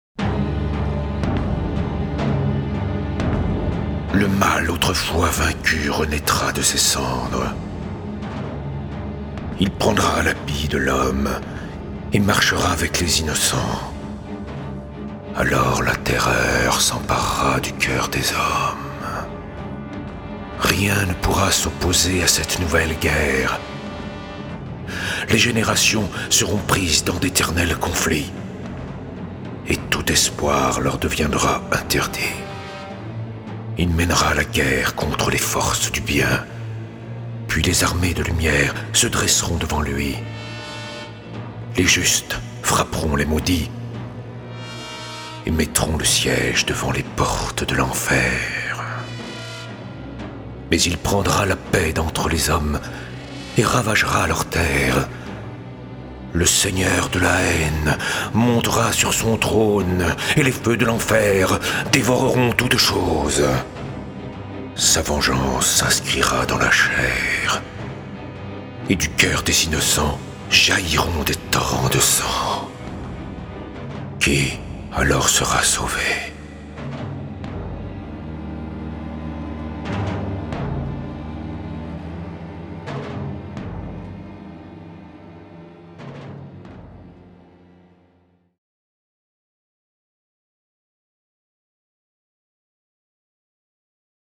Extrait fiction audio